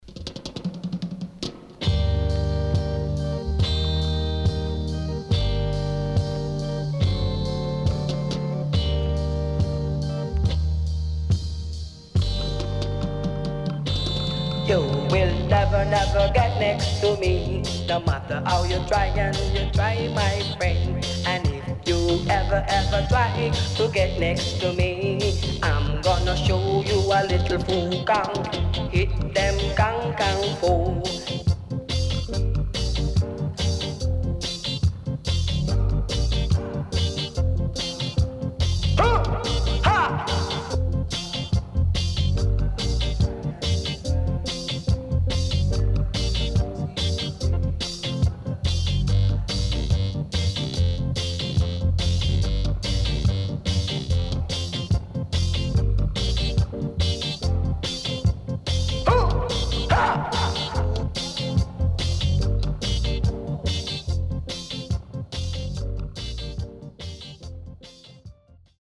HOME > REGGAE / ROOTS  >  KILLER & DEEP
SIDE A:少しチリノイズ入りますが良好です。